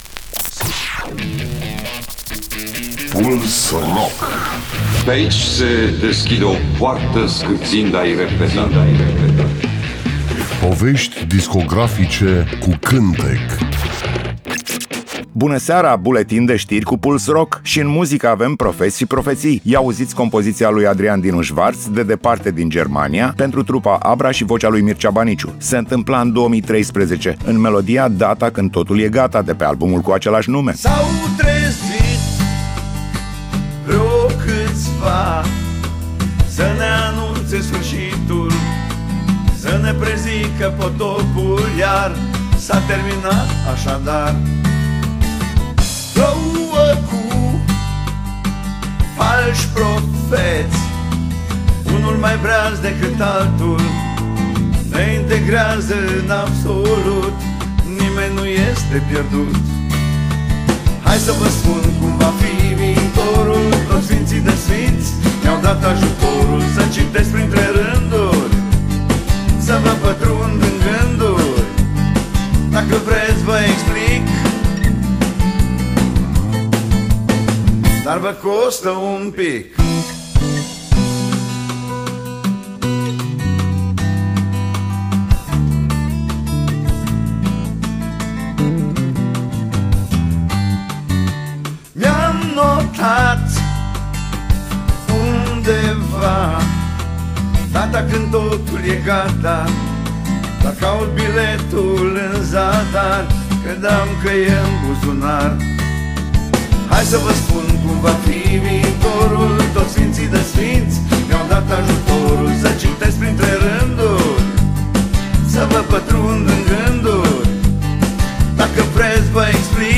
Emisiunea se numește Puls Rock și jonglează cu artiștii noștri rock, folk, uneori chiar jazz.